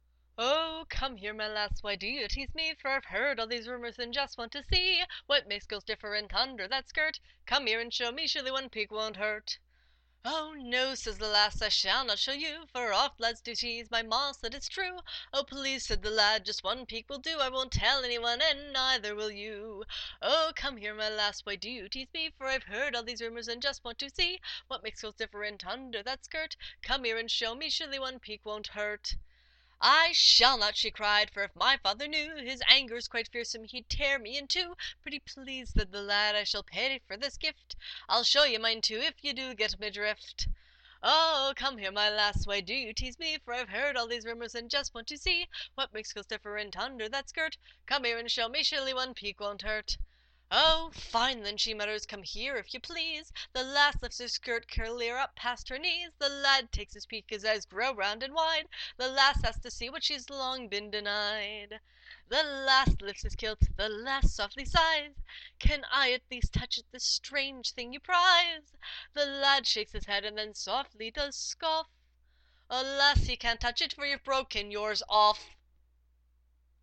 Sung to the tune of “Irish Washer Woman”